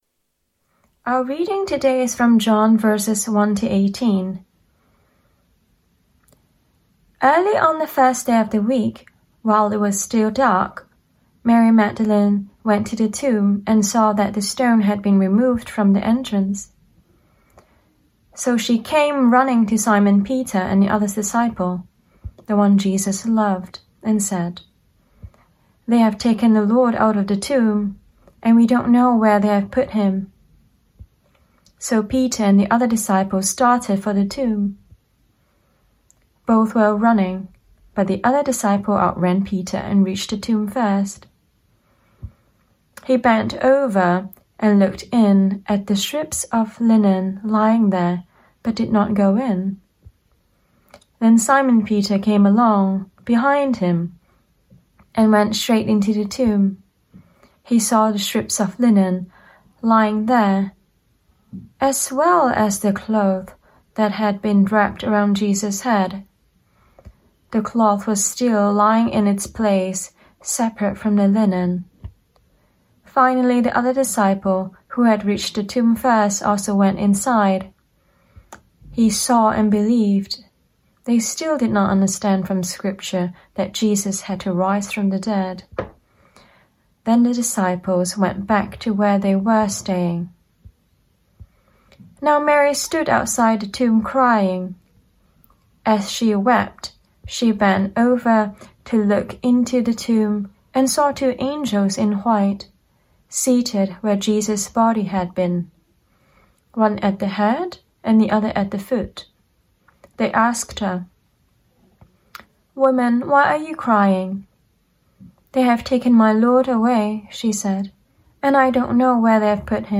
Our Easter Sunday Service preached online